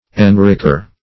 Enricher \En*rich"er\, n. One who enriches.